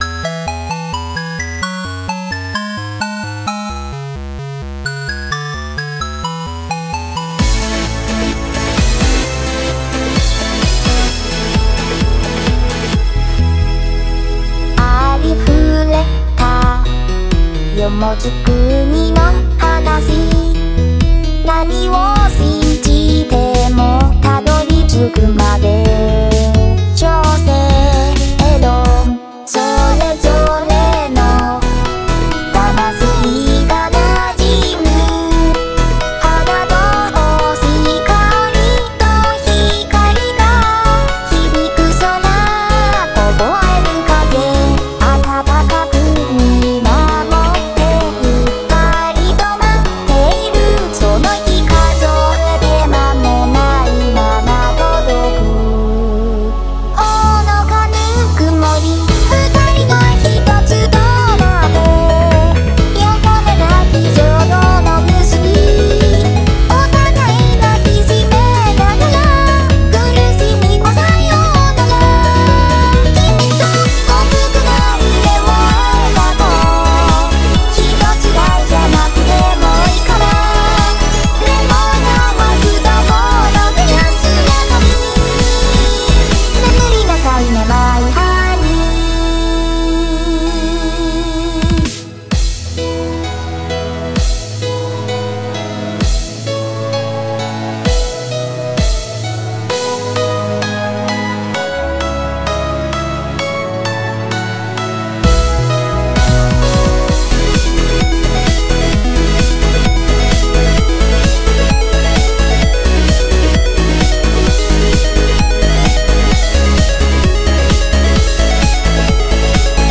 BPM130
Comment: When I first heard it, it's so peaceful and cute.